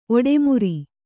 ♪ oḍemuri